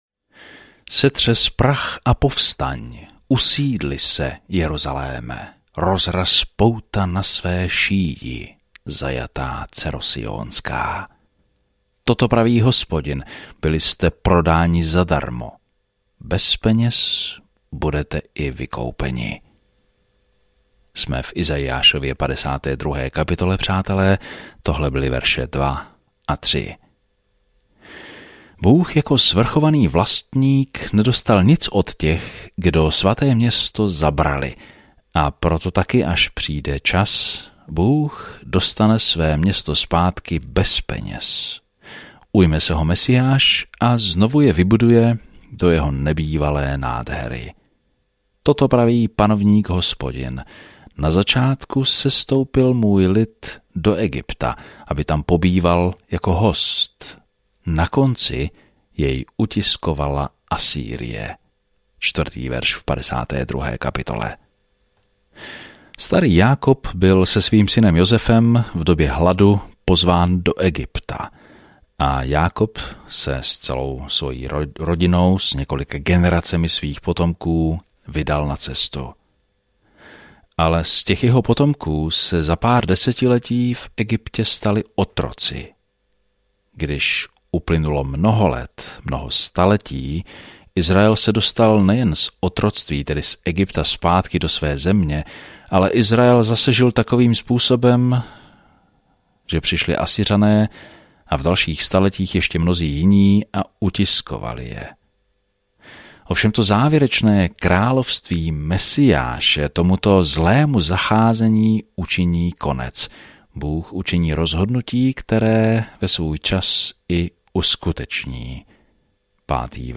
Písmo Izaiáš 52:3-15 Den 56 Začít tento plán Den 58 O tomto plánu Izajáš, nazývaný „páté evangelium“, popisuje přicházejícího krále a služebníka, který „ponese hříchy mnohých“ v temné době, kdy Judu dostihnou političtí nepřátelé. Denně procházejte Izajášem a poslouchejte audiostudii a čtěte vybrané verše z Božího slova.